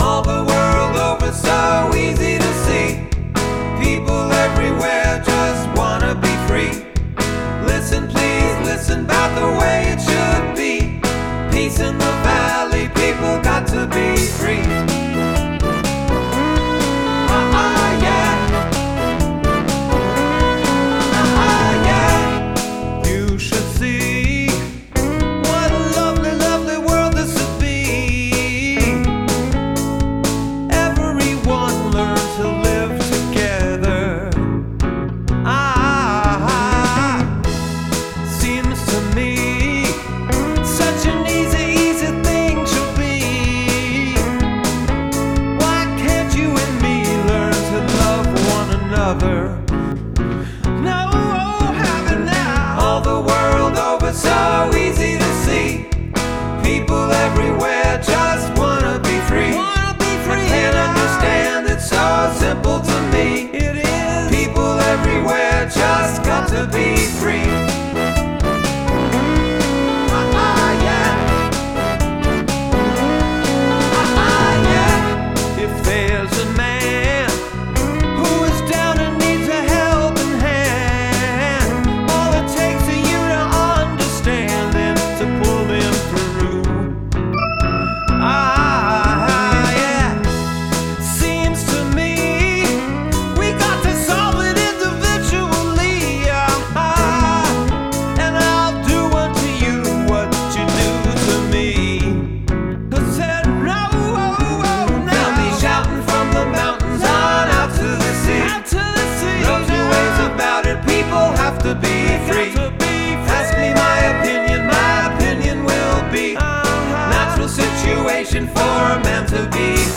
The Recording Studio